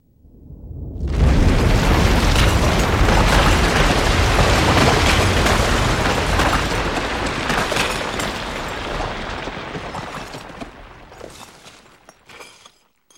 Здесь собраны разные варианты: от далеких раскатов до близкого схода снежной массы.
Грохот снежной массы